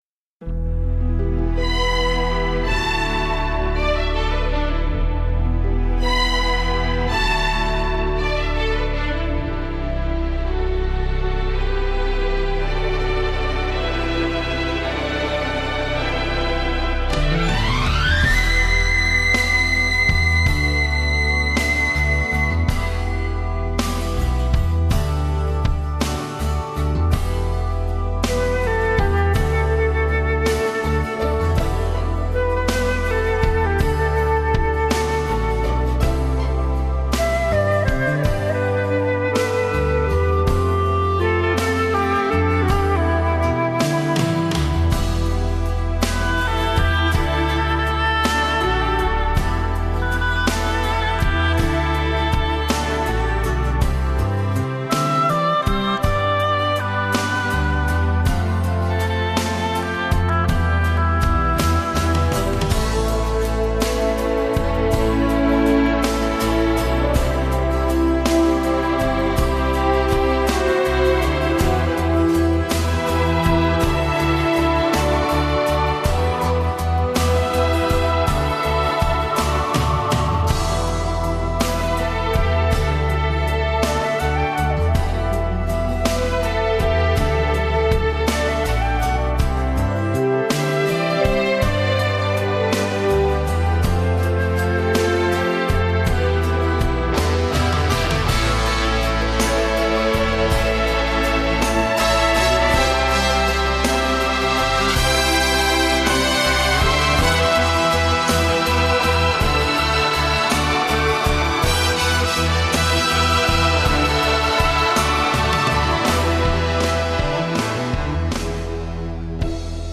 02 Viennese Waltz